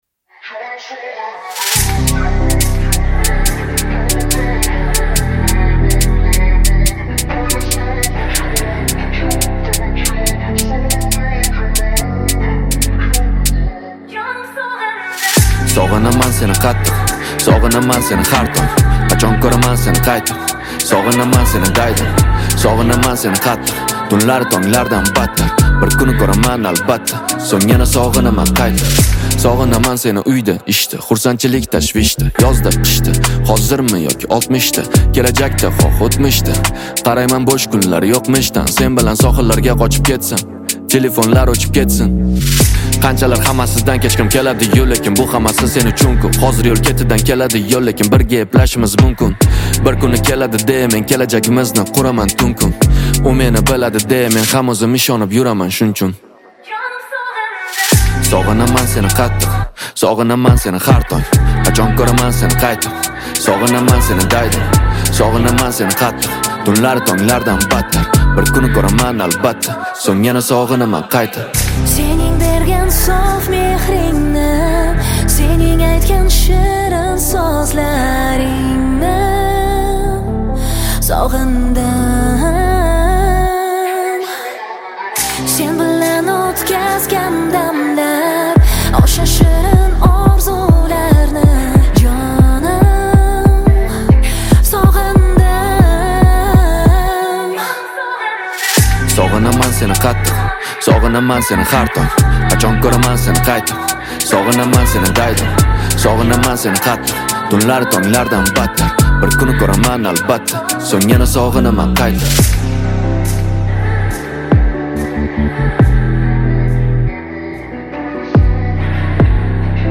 • Категория: Узбекская музыка